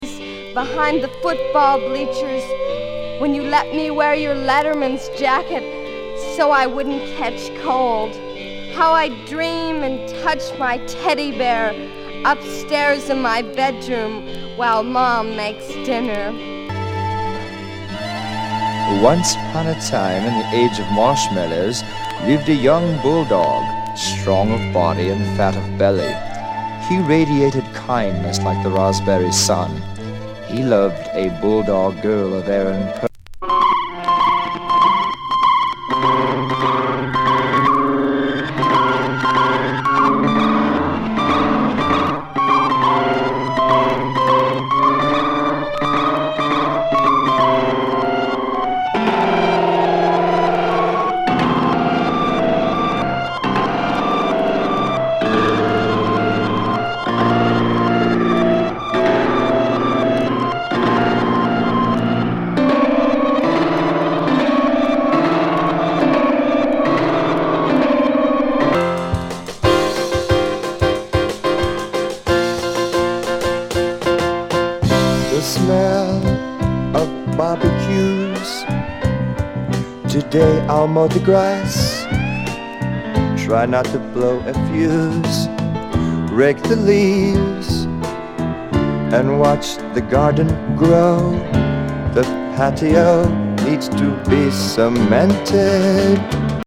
爆裂ロック～マーシャル・マクルーハン的？お喋りスポークン・ワード、謎トイ電子ノイズ～カントリーポップ。